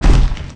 foot.wav